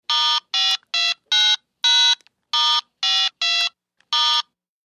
Cell phone dialing 9 digits